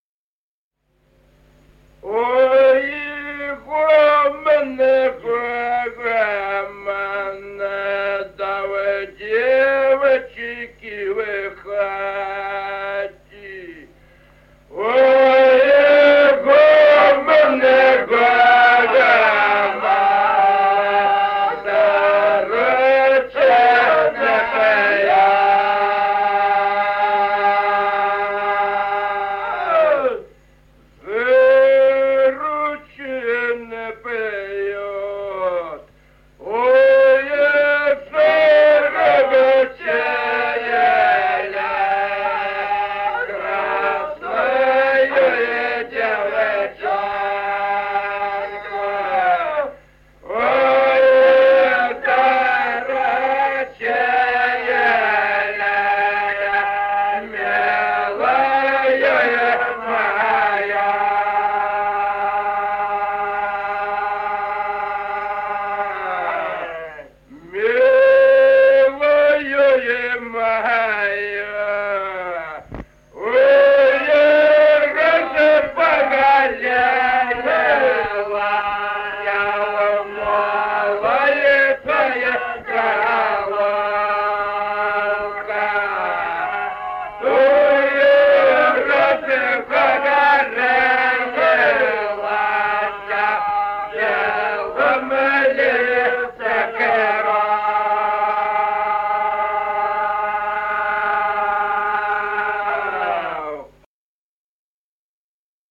Народные песни Стародубского района «Ой, гомон, гомон», лирическая.
с. Остроглядово.